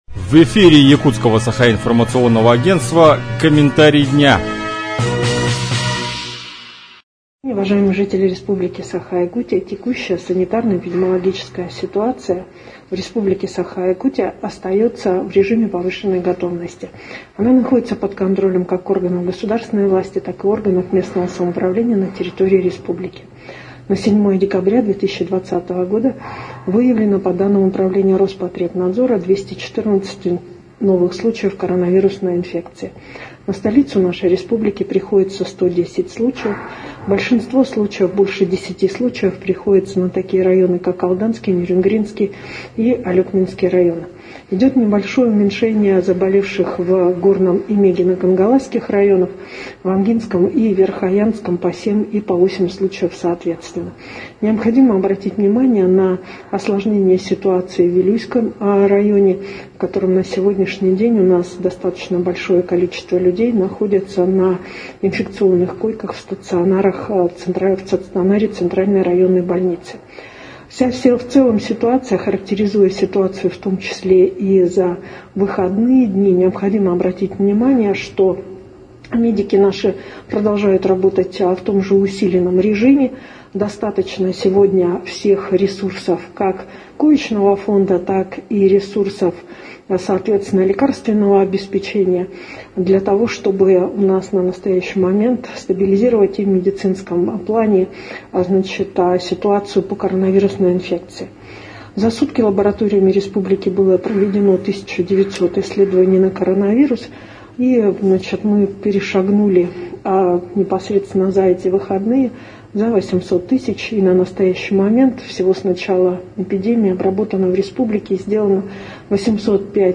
Про обстановку в Якутии на 7 декабря рассказал вице-премьер Якутии Ольга Балабкина.